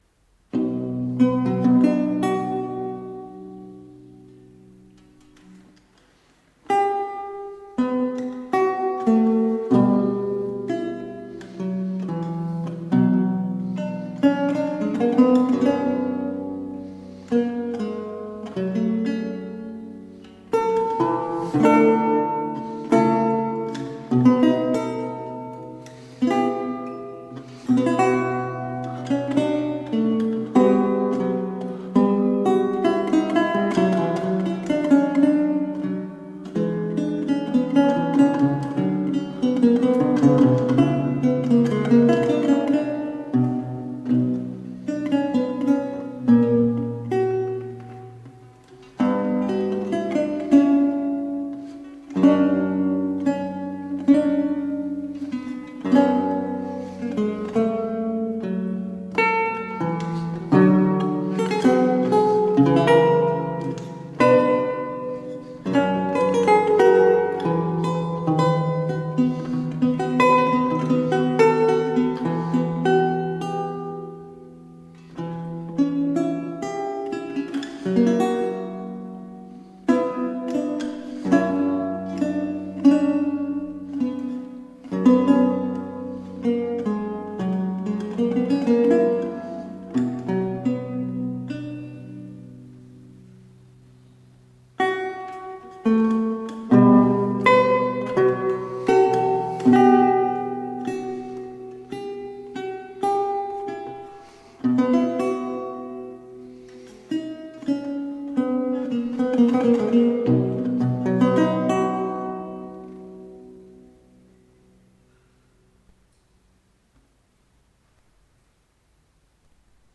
Classical, Renaissance, Instrumental
Lute